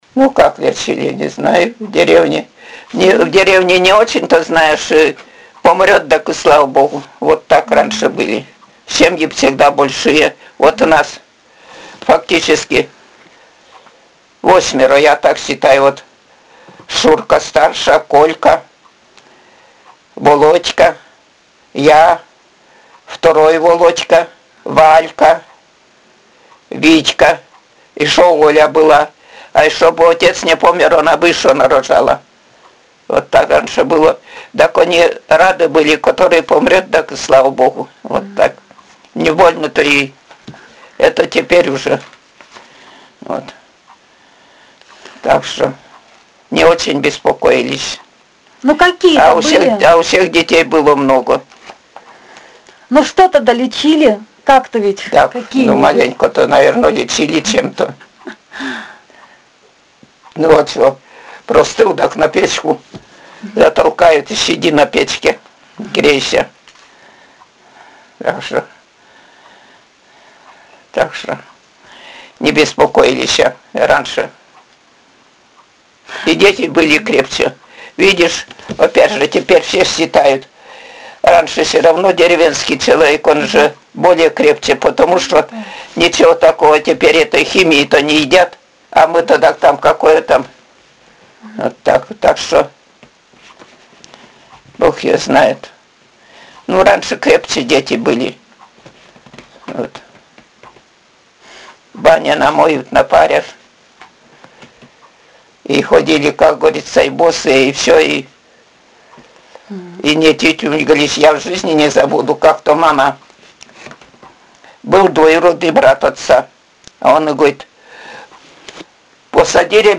— Говор северной деревни
Пол информанта: Жен.
Аудио- или видеозапись беседы: